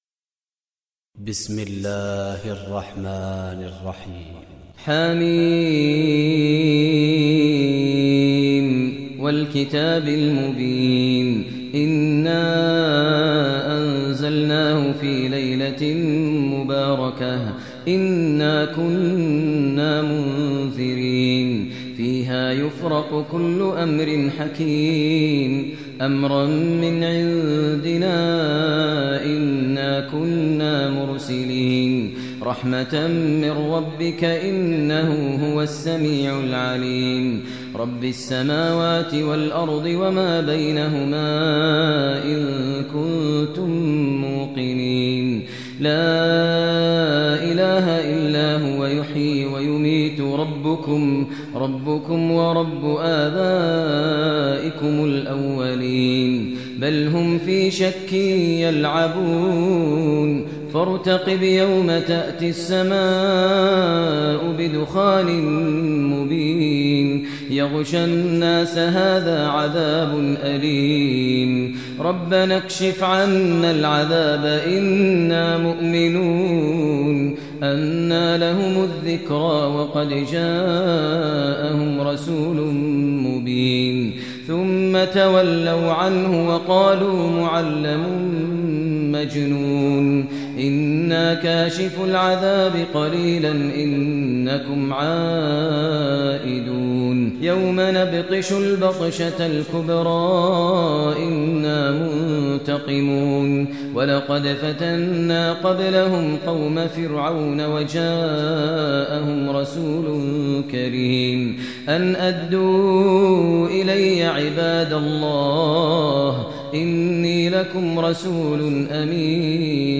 Surah Dukhan Recitation by Sheikh Maher al Mueaqly
Surah Dukhan, listen online mp3 tilawat / recitation in the voice of Sheikh Maher al Mueaqly.